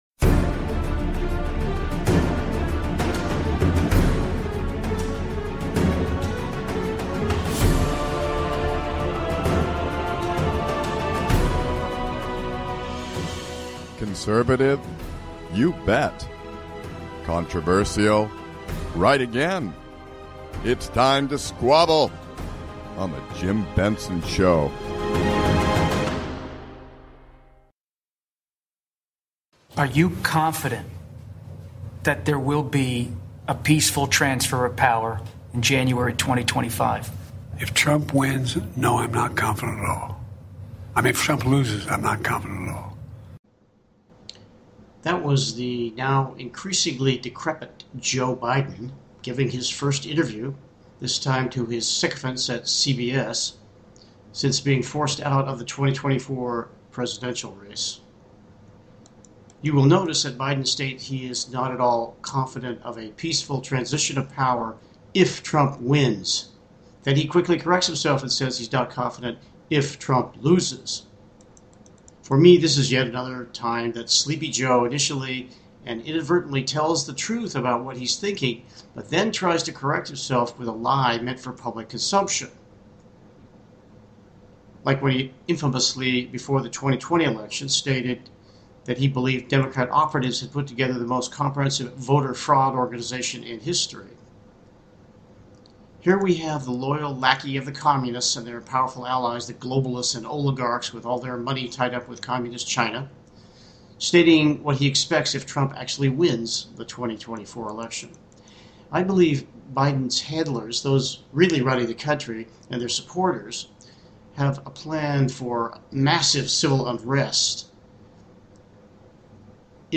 conservative talk radio done right, addressing the issues that concern you.